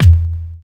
Wu-RZA-Kick 58.wav